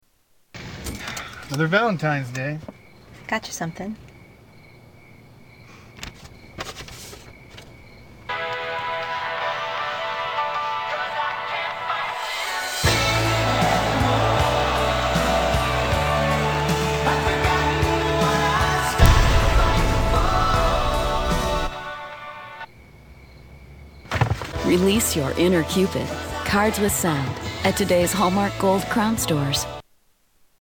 Tags: Holidays Valentines Day Movies TV Shows Commercials